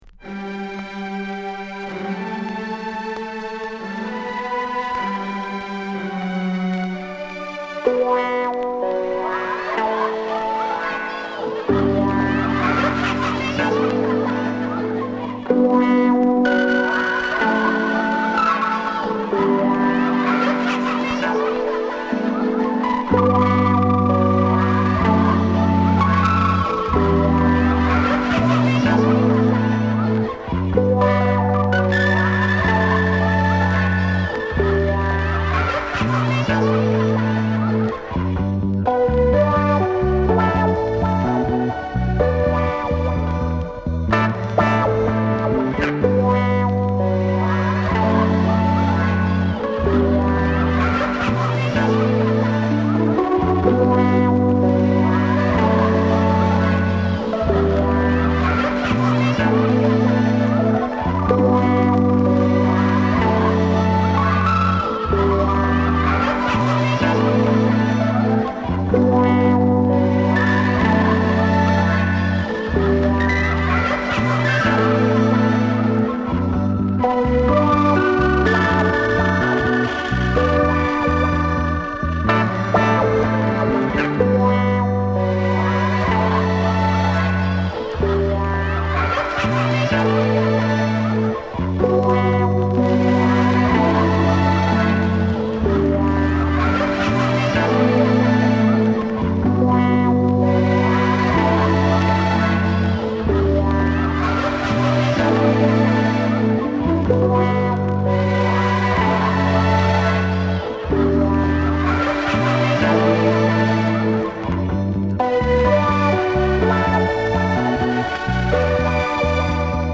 チルアウトまどろみトロけてな最高チューン